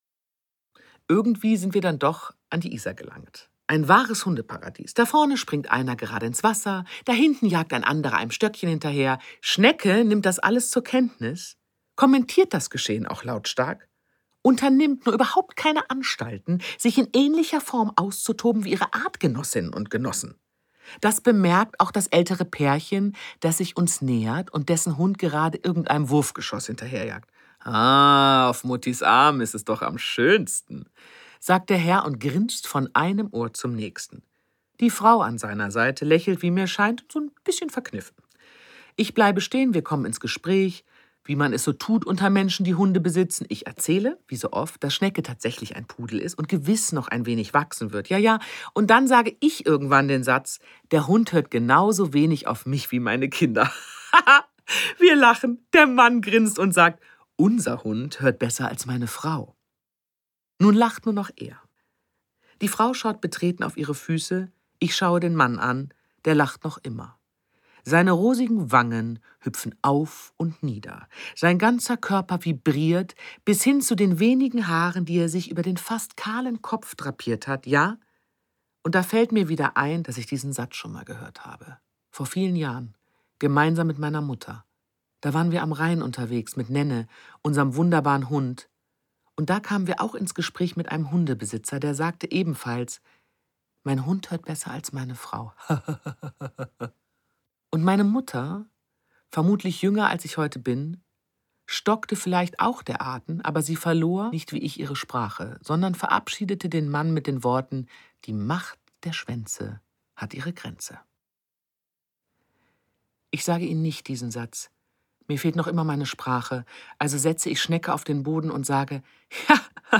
Cover Print Cover Web Hörprobe MP3